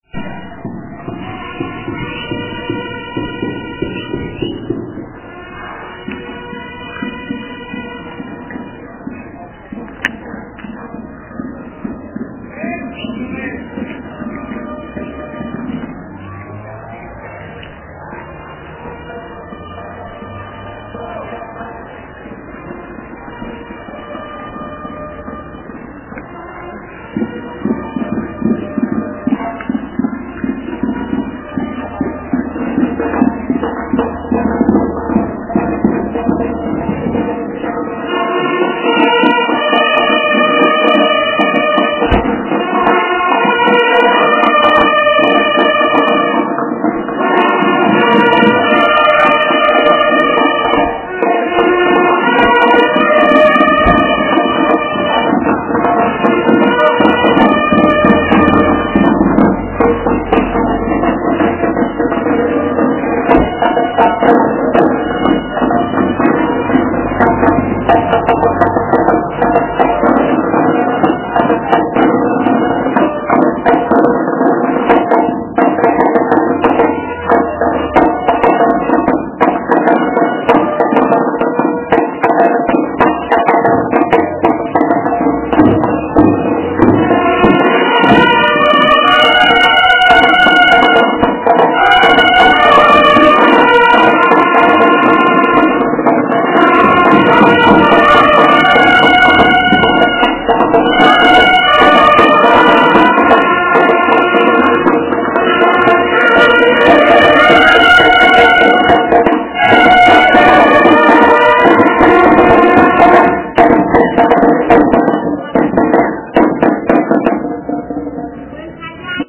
Because Independence Day is coming up soon (Nov. 2nd), all the high school bands are preparing. Band practice just let out and these guys played in the center of town for about three hours....the SAME song over and over.
sona_band.mp3